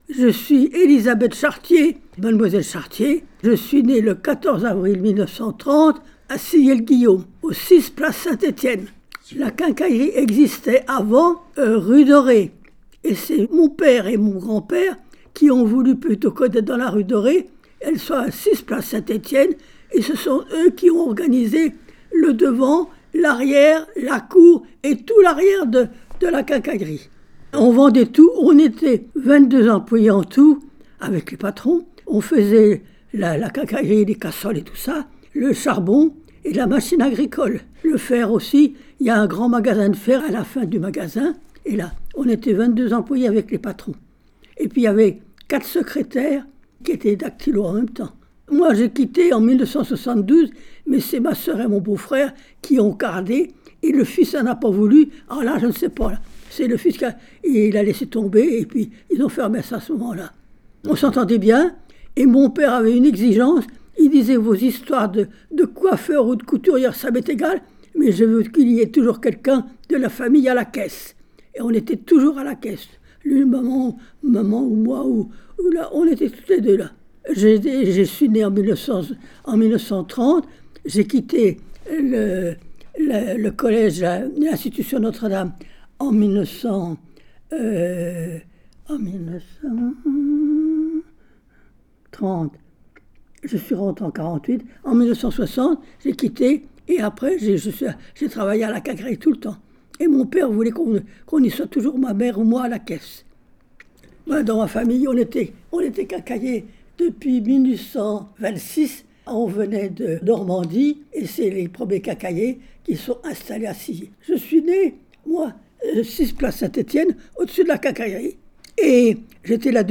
Petites Cités de Caractères - Parcours sonore Sillé
Témoignage